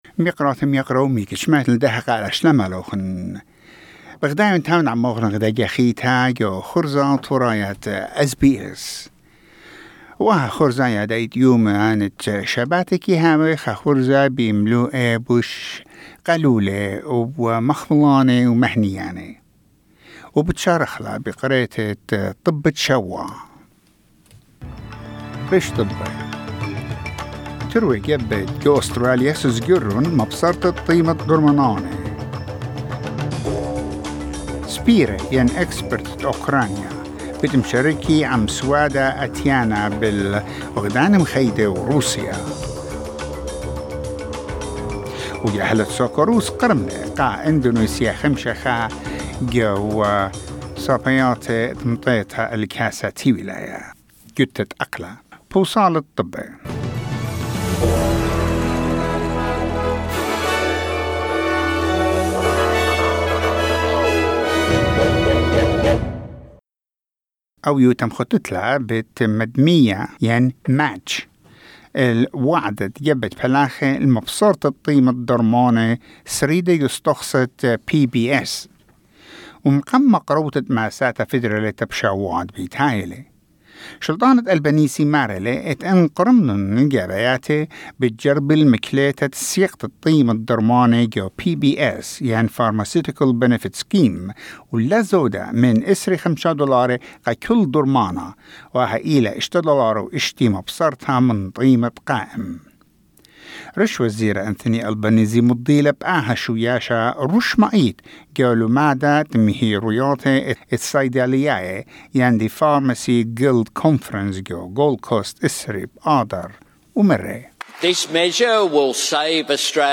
SBS Assyrian: Weekly news wrap